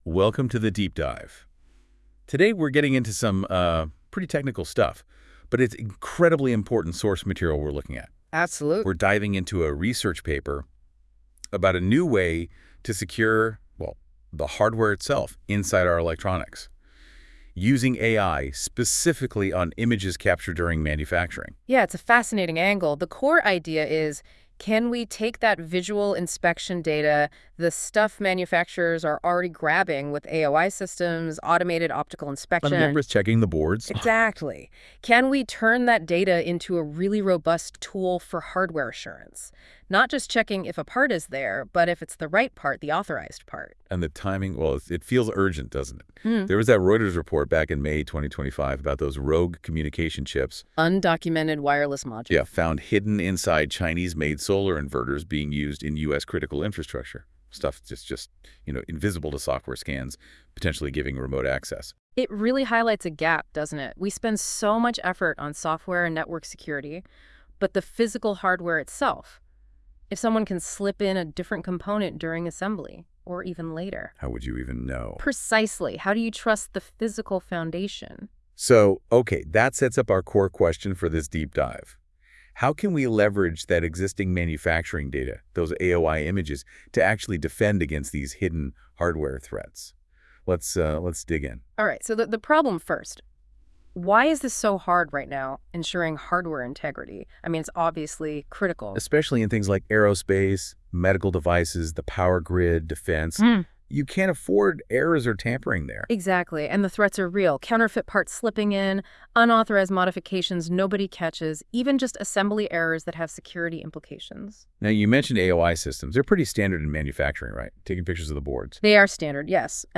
In this AI-generated podcast we explore a recent paper